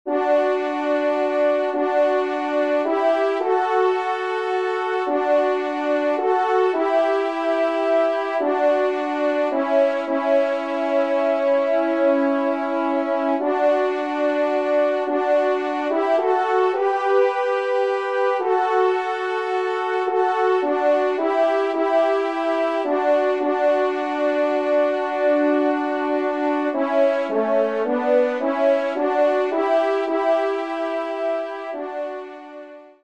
ENSEMBLE (Musique)